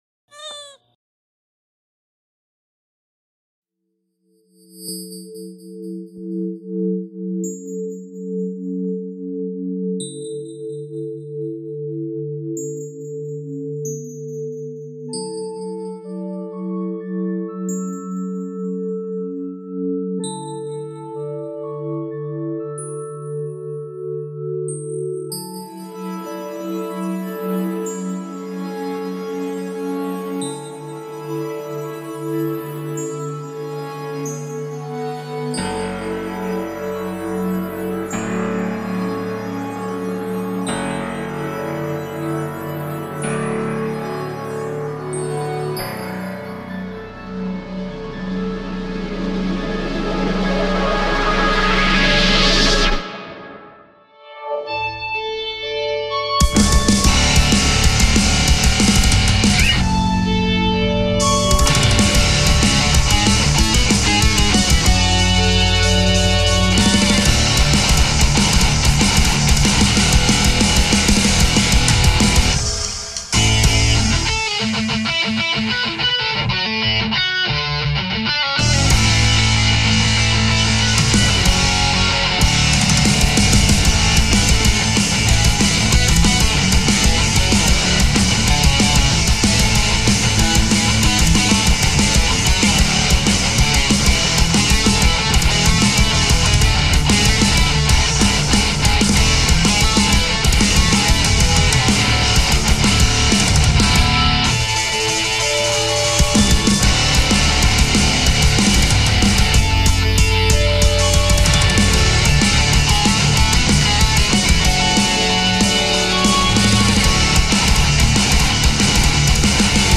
Song guitar tuning is in Drop D.